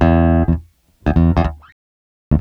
Bass Lick 34-10.wav